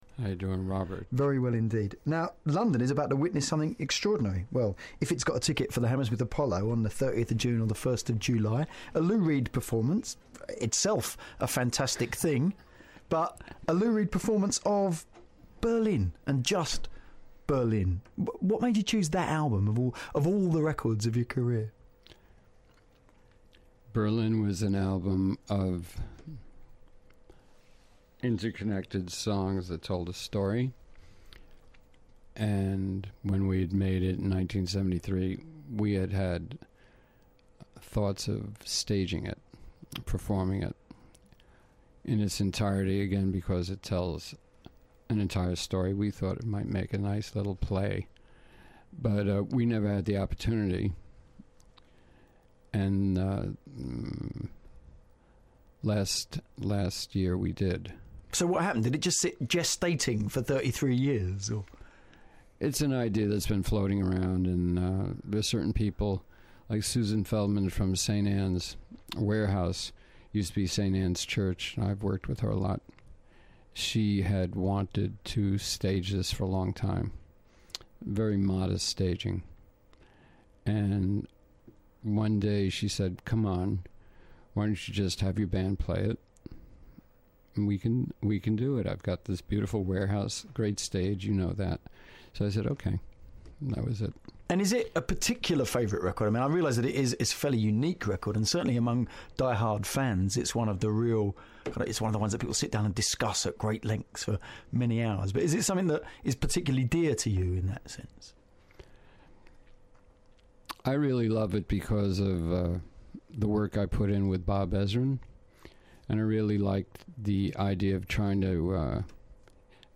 Lou Reed came into BBC London 94.9 for an interview with Robert Elms. It was in June 2007 when he was playing the whole Berlin album live.